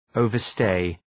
Προφορά
{,əʋvər’steı}